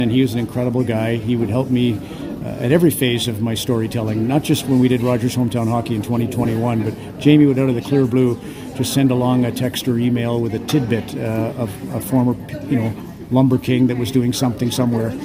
Fast forward many years to Thursday when MacLean addressed an audience at Algonquin College as one of the key note speakers at the 11th Annual Business & Leadership Conference.